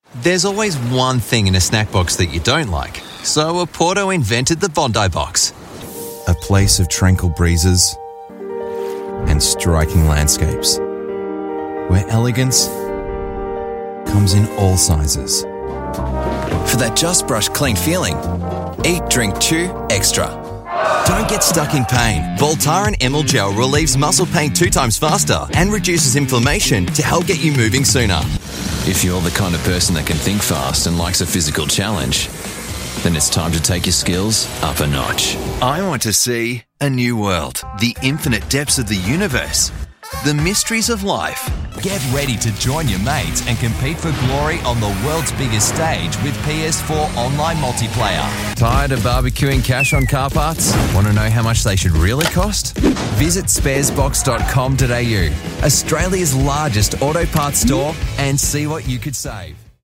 Male
Television Spots
Commercial Reads
Words that describe my voice are Australian Accent, Versatile, Professional.